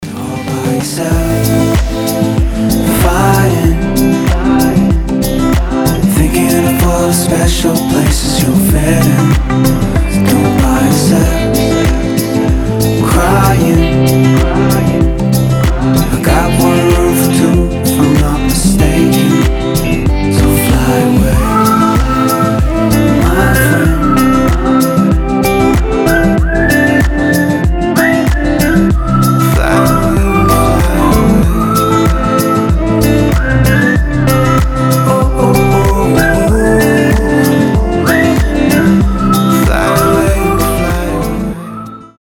Мелодичный хаус - рингтон